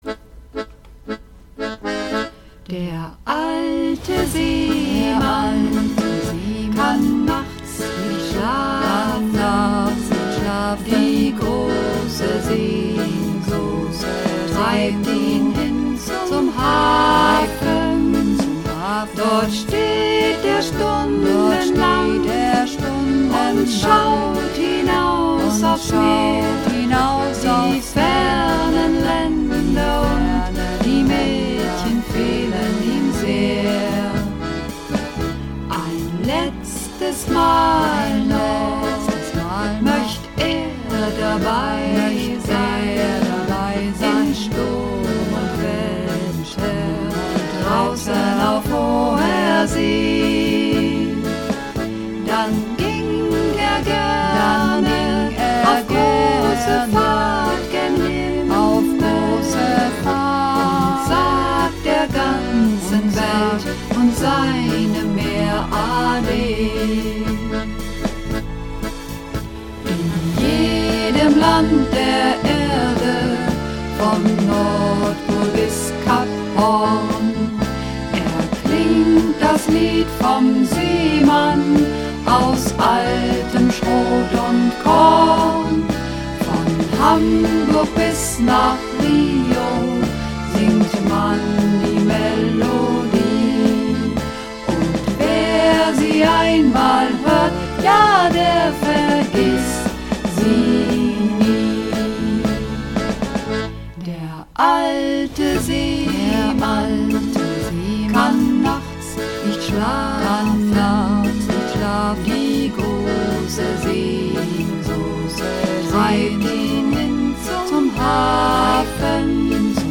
Übungsaufnahmen - Der alte Seemann
Runterladen (Mit rechter Maustaste anklicken, Menübefehl auswählen)   Der alte Seemann (Mehrstimmig)
Der_alte_Seemann__4_Mehrstimmig.mp3